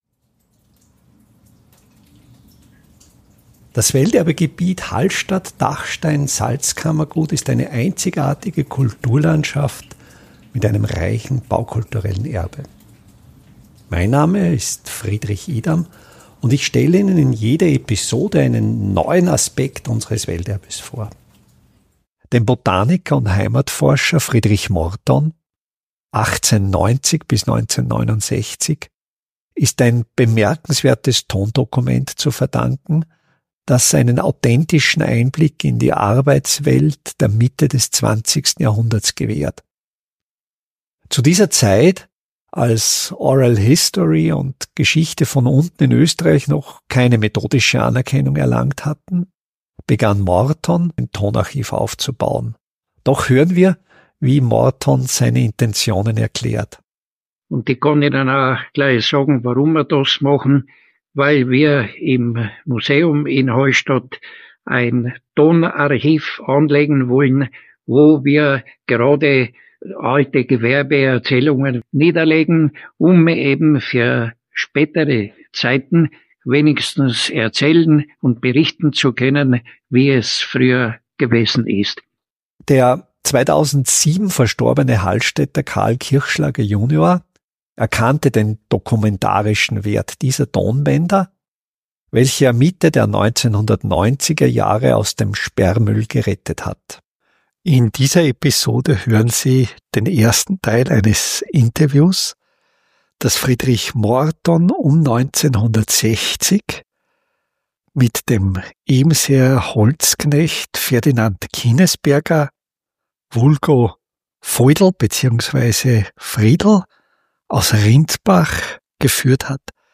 Ebensee – Oral History – Holzknecht 1 ~ Welterbe Hallstatt Podcast
Tondokumente aus den 1960er Jahren